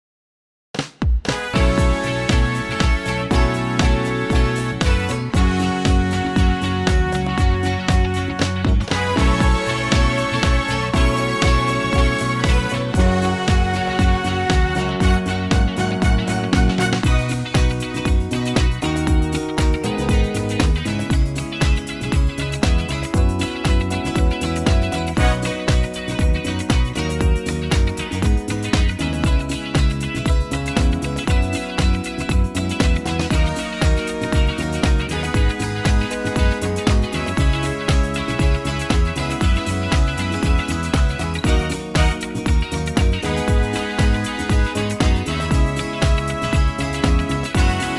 key - B - vocal range - C# to E
Superbly fresh and punchy arrangement